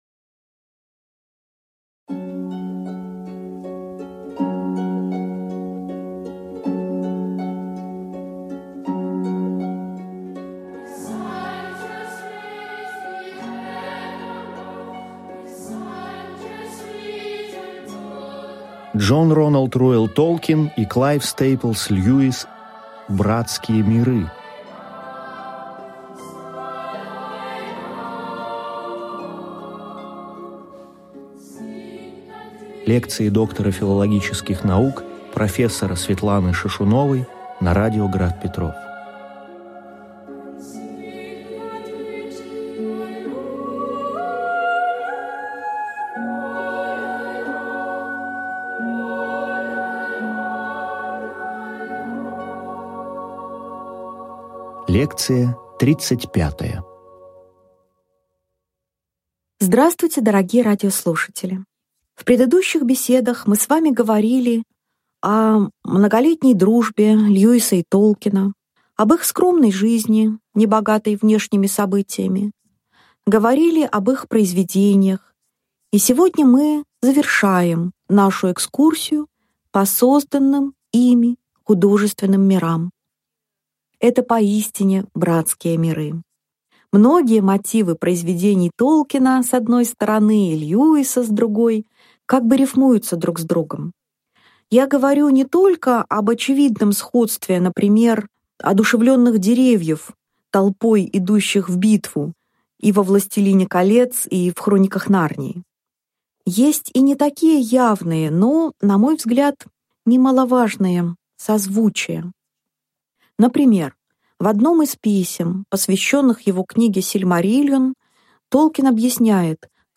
Аудиокнига Лекция 35. Дж.Р.Р.Толкин и К.С.Льюис: родство художественных миров | Библиотека аудиокниг